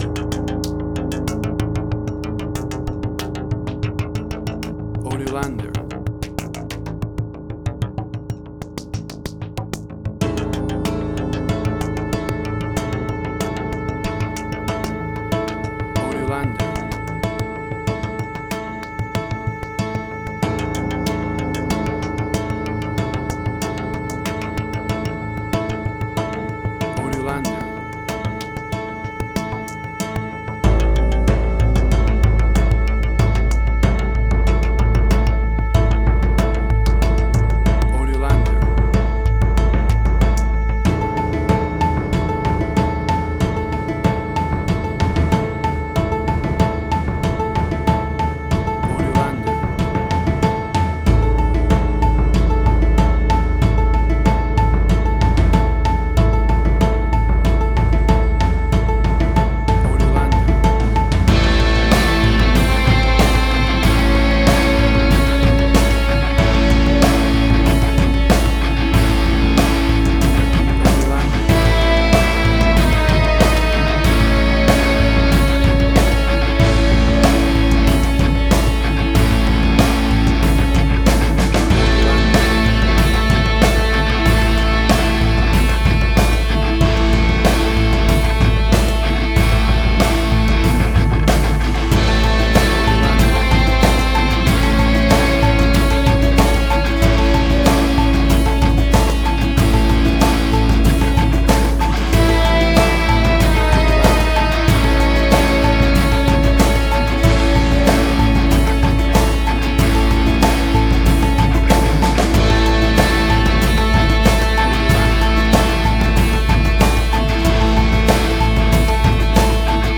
Cinematic Industrial Sci-fi
WAV Sample Rate: 16-Bit stereo, 44.1 kHz
Tempo (BPM): 94